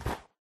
step / snow2